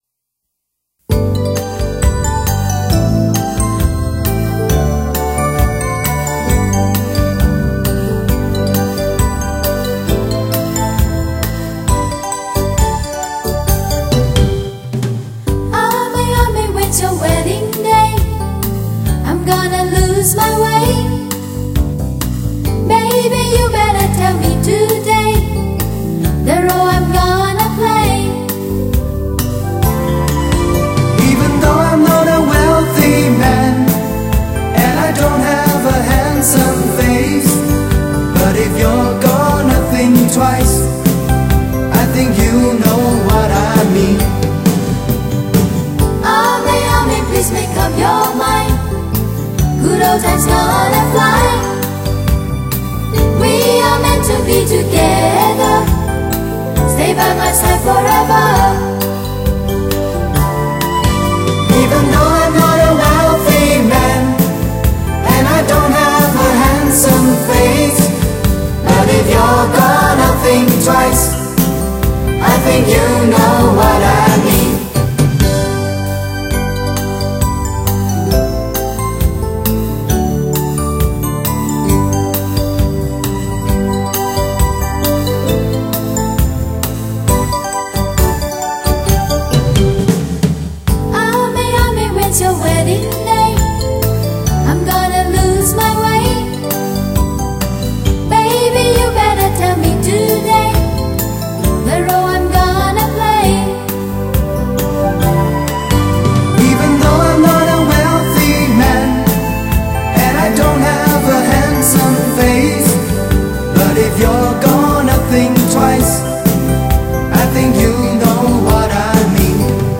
听洋人诠释我们的民歌